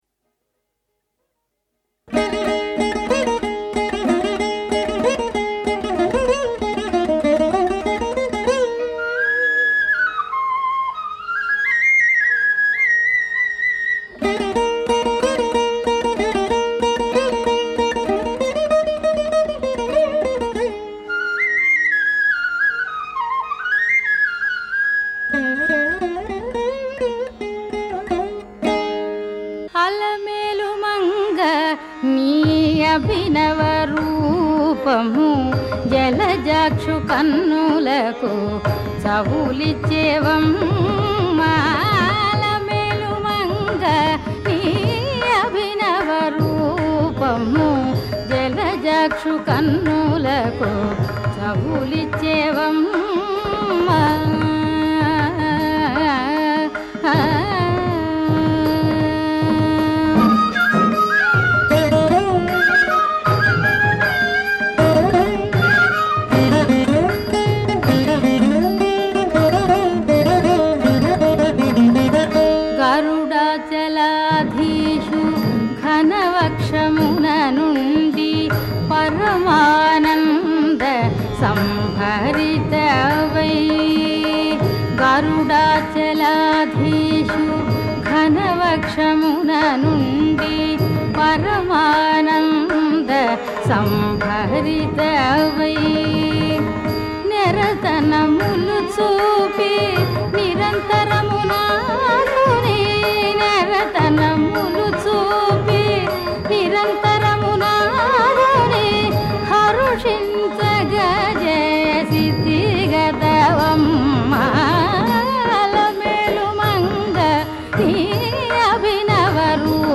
సంగీతం